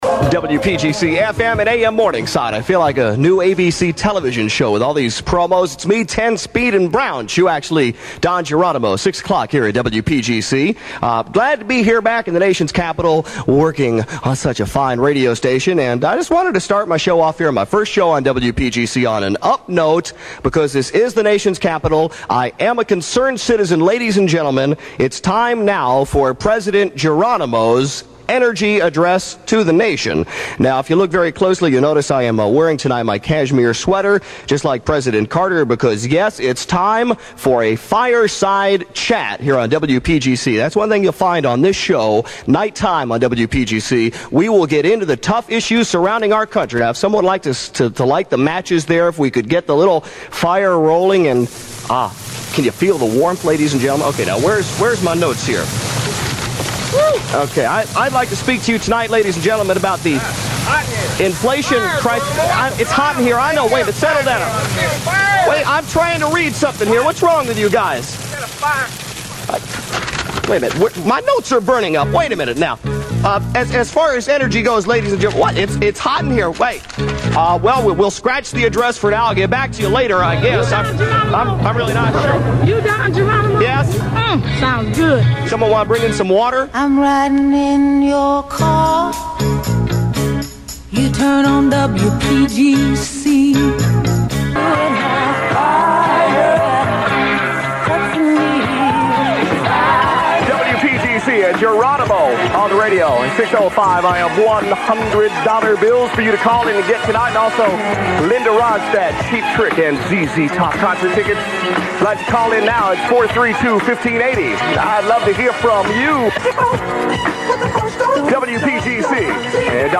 His impact was immediate and long felt; during his tenure WPGC righted itself and regained the CHR crown in D C. The war with Q107 was well underway at the time of these airchecks.
To combat the attrition, Scott Shannon brought in Don Geronimo whose appeal went beyond the acne crowd to young Adults as evidenced by every single phone caller being obviously over the age of 20. Many of those calls took the form of his 'Not Ready For Nighttime Players' who provided the comic fodder for numerous bits.